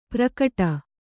♪ prakaṭa